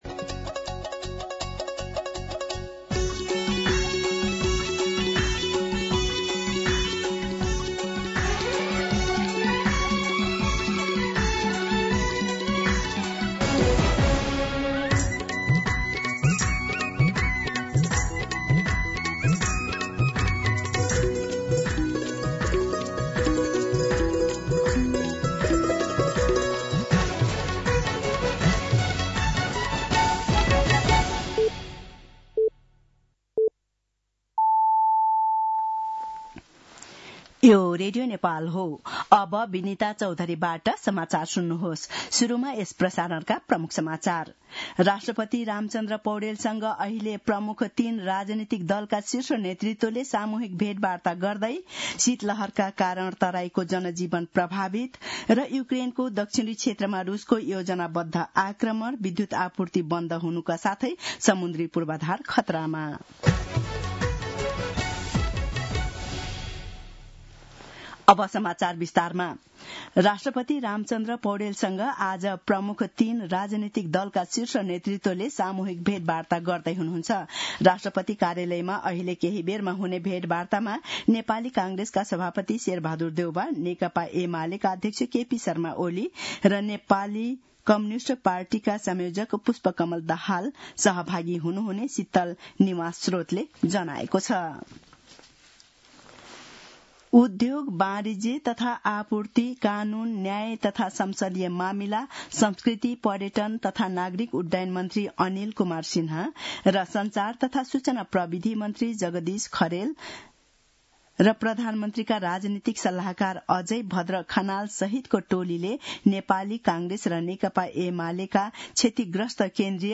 दिउँसो ३ बजेको नेपाली समाचार : ८ पुष , २०८२